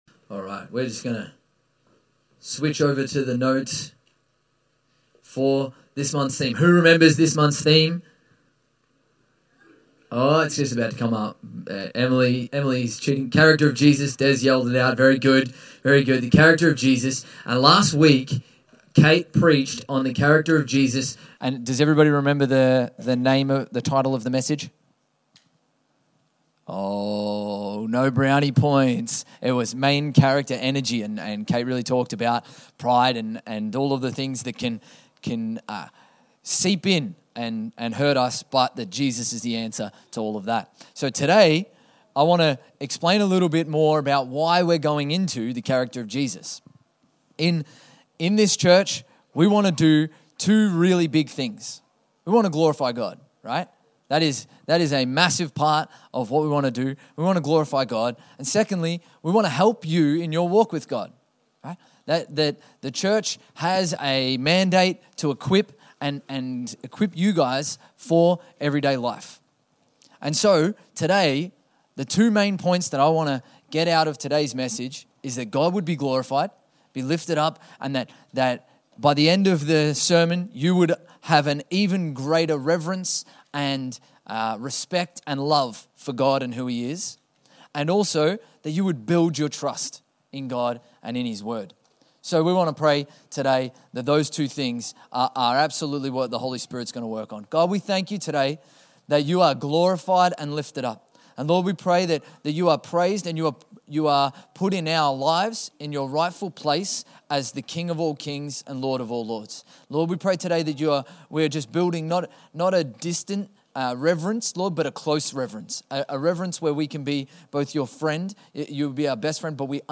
Infinity Church Podcast - English Service | Infinity Church